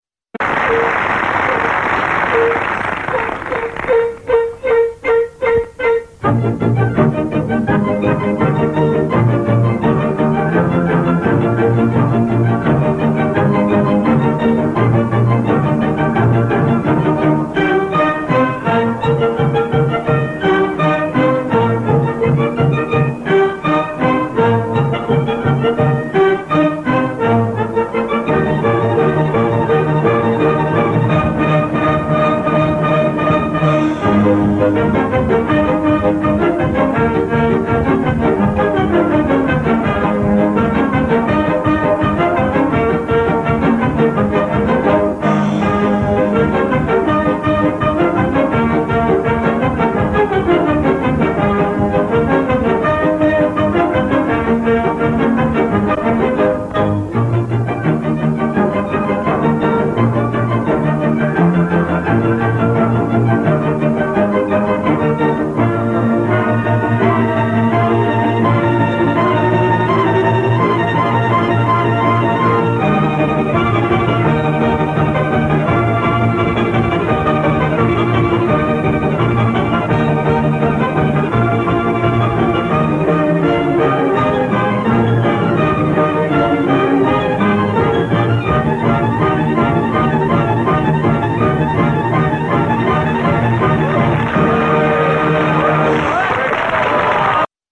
Can-can
Origin: Galop
Music Hall CanCan UK 1943.mp3